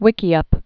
(wĭkē-ŭp)